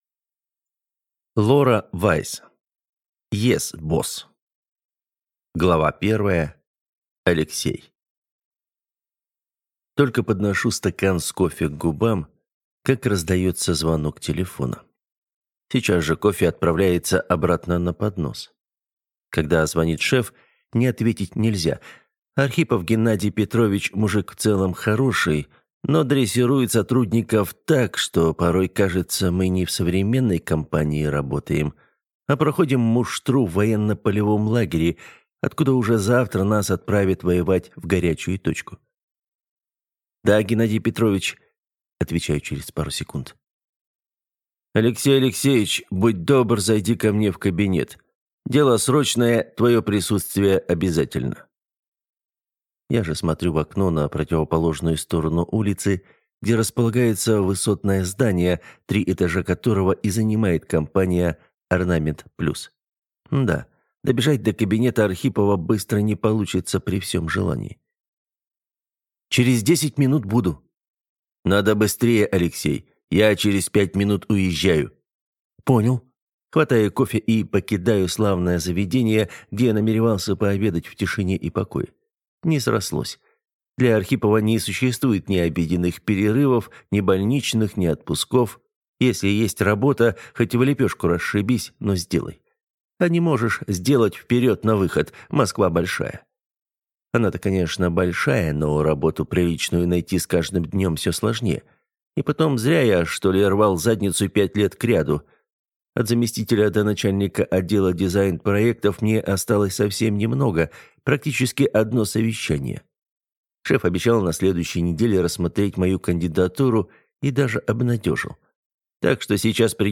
Аудиокнига Yes, Boss | Библиотека аудиокниг